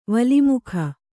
♪ vali mukha